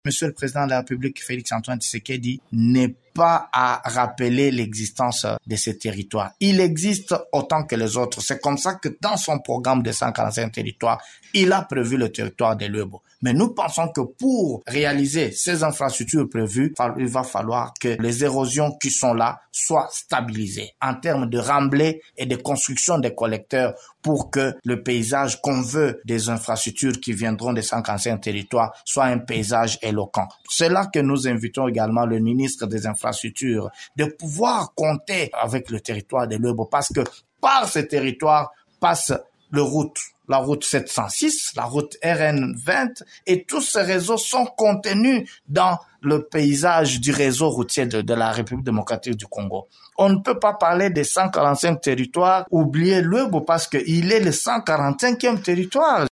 Déjà presque tous les bâtiments publics sont partis, en dehors des conditions socio-économiques qui sont précaires, s’indigne Olivier Tombe :